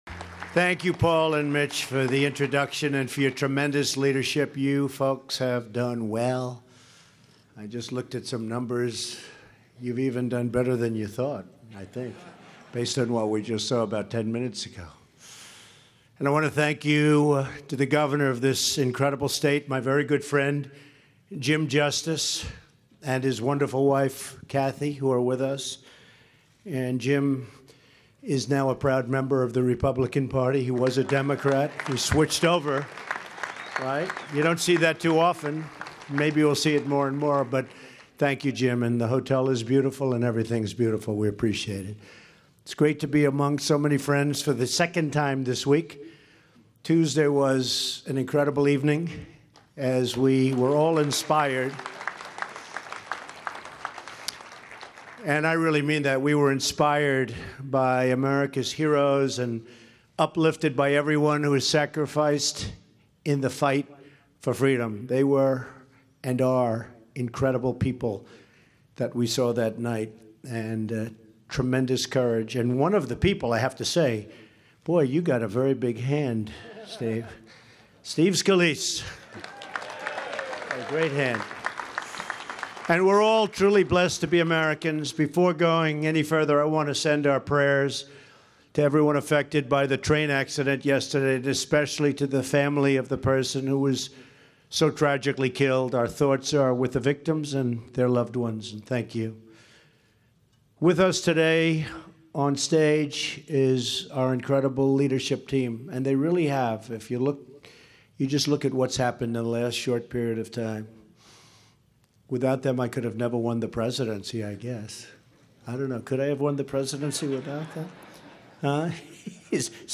February 1, 2018: Remarks at the House and Senate Republican Member Conference
President Trump_Speaks_2018_House_Senate_Republican_Member_Conference.mp3